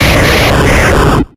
Cries
ELECTRODE.ogg